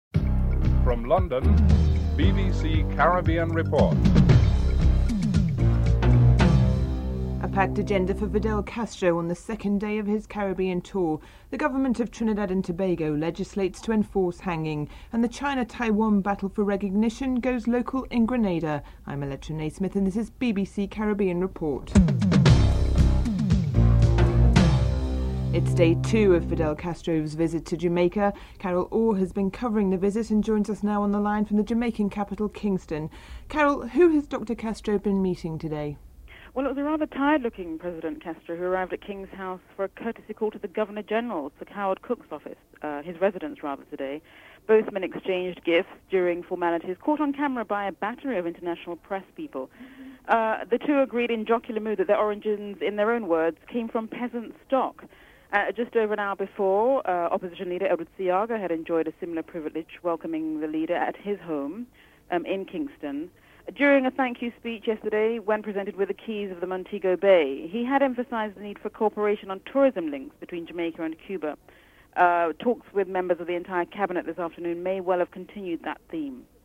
3. The government of Trinidad and Tobago legislates twenty-one hangings. Attorney General Ramesh Lawrence is interviewed